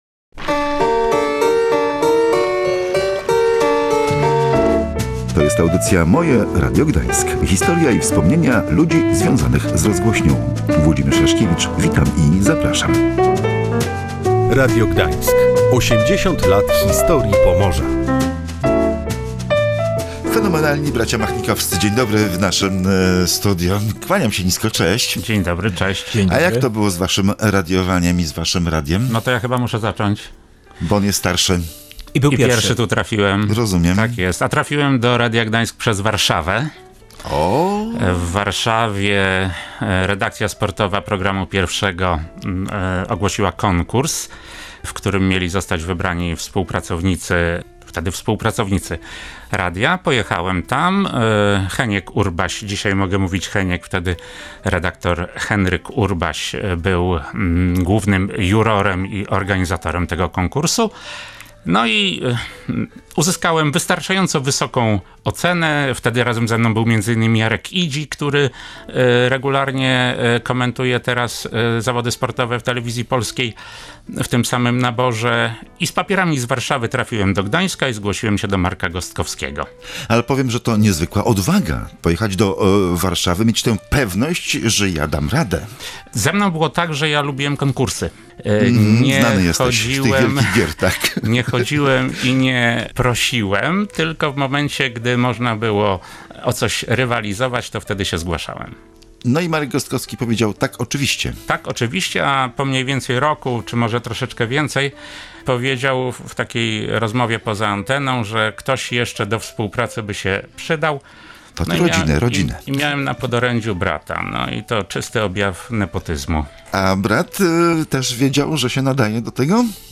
Sport zawsze był ich pasją, a jak odkrywali umiejętność opowiadania o nim? Posłuchajcie rozmowy